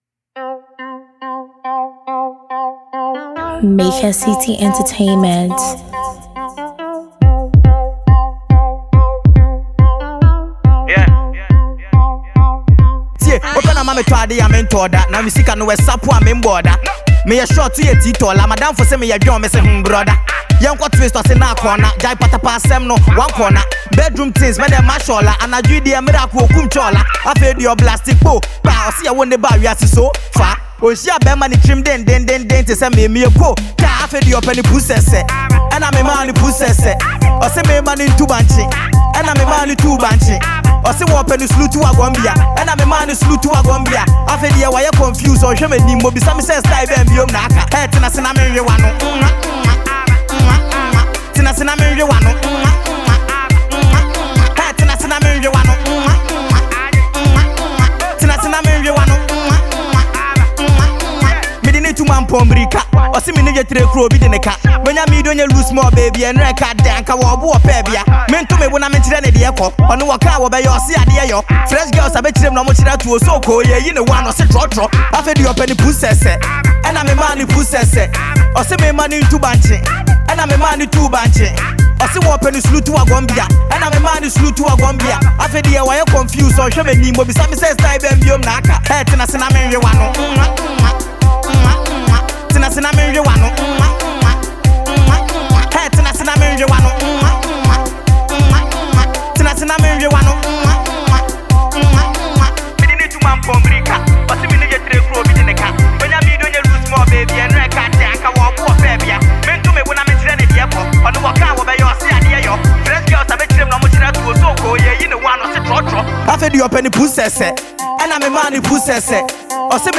Ghana Music Listen
Ghanaian sensational singer